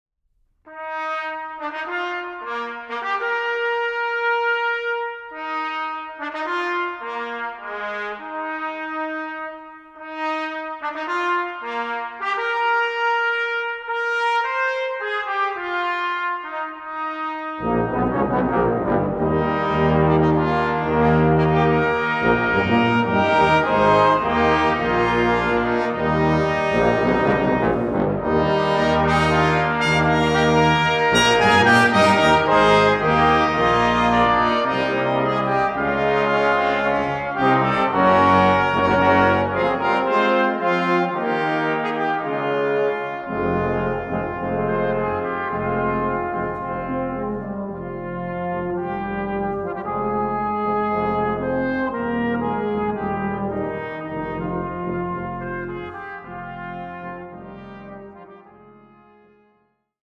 Fantasia for brass ensemble